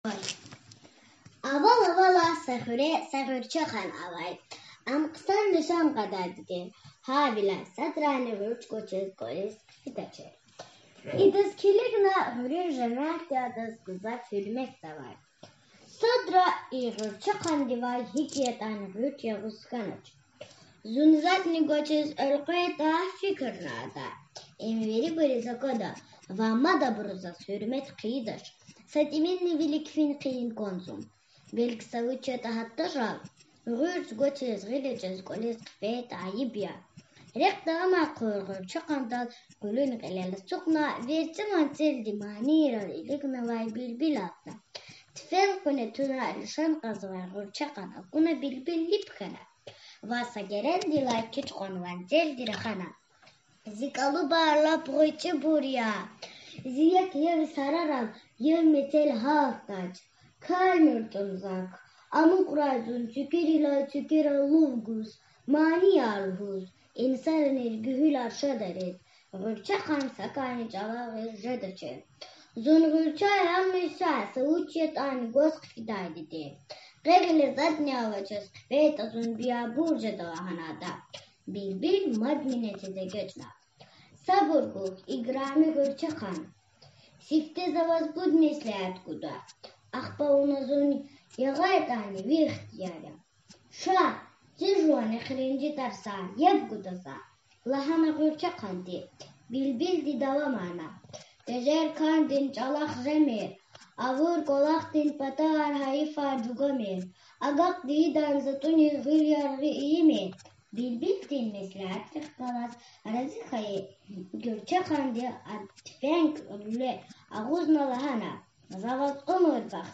Гъуьрчехъанни билбил (аудио мах)